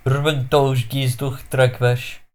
File File history File usage Yrvangtalzhgiztoxtrakvash.ogg (file size: 58 KB, MIME type: application/ogg ) Xtrakva Endonym pronunciation File history Click on a date/time to view the file as it appeared at that time.